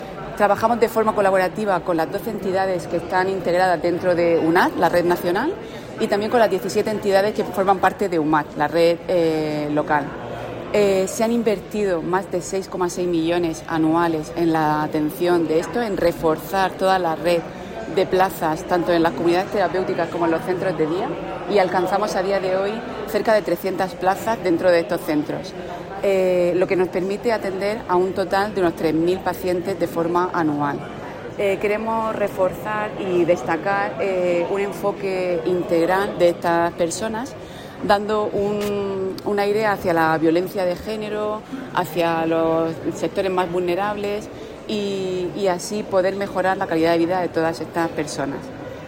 Sonido/ Declaraciones de la gerente del Servicio Murciano de Salud, Isabel Ayala, sobre la Red UNAD.